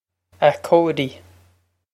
A Cody Ah Cody
This is an approximate phonetic pronunciation of the phrase.